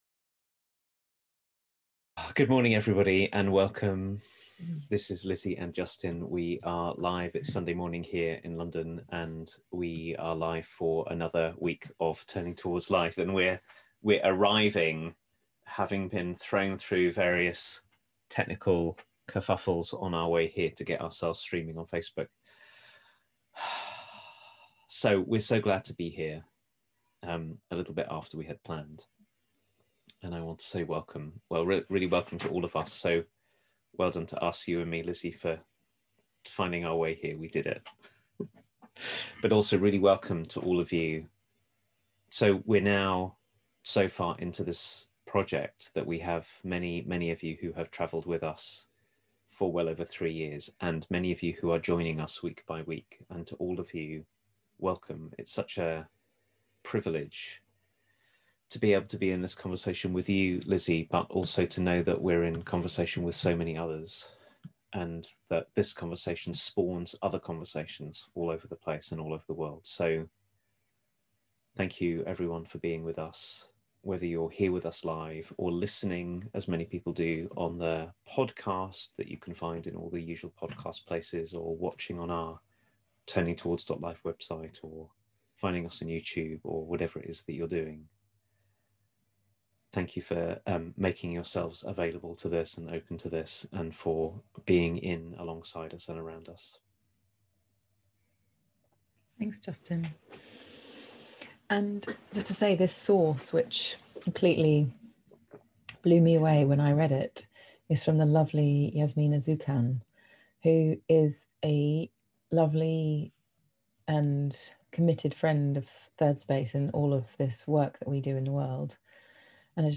So how about learning to connect to the memory of our vastness, and our intimacy with all of life, when we are triggered to retreat? This episode of Turning Towards Life is a conversation about remembering the ways in which we already belong to life, and what might come from that